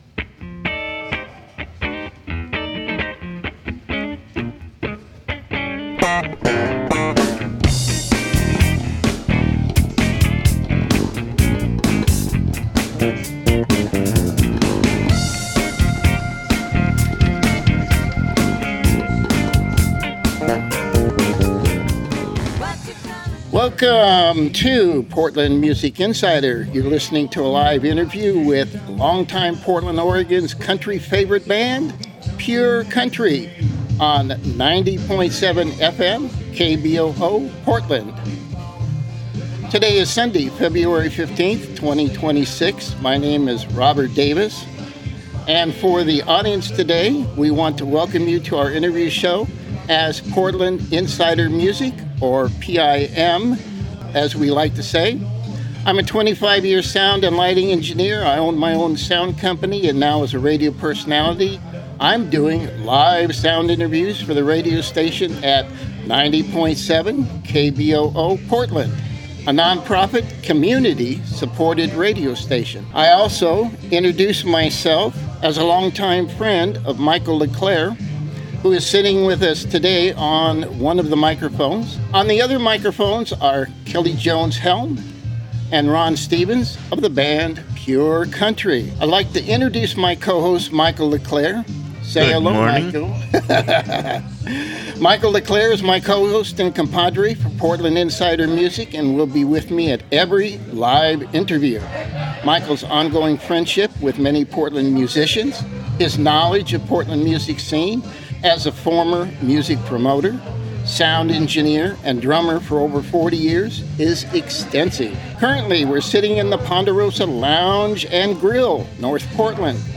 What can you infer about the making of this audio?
Our PMI interview and their live performance songs will be released soon on PMI and PMI Radio as well as KBOO radio at KBOO Radio .